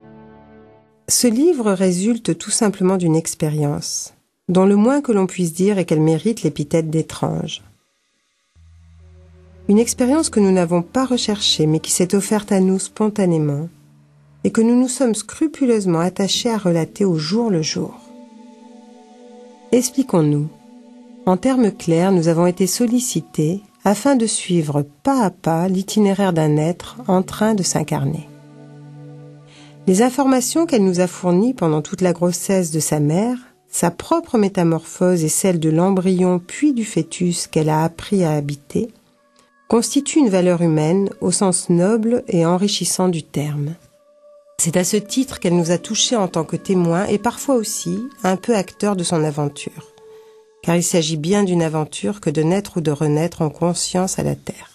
C'est par conséquent un nouveau regard qui est proposé ici sur la vie foetale ainsi que sur le processus de réincarnation. D'une écriture simple et directe, ce livre-audio, par son originalité et la somme d'informations qu'il offre, parlera sans nul doute, non seulement à ceux qui s'apprêtent à donner naissance à un enfant ou qui l'ont déjà donnée, mais aussi à tous ceux pour qui la vie est une perpétuelle source d'émerveillement. 14 , 40 € Prix format CD : 18,00 € Ce livre est accessible aux handicaps Voir les informations d'accessibilité